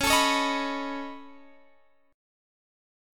Db7sus2#5 Chord
Listen to Db7sus2#5 strummed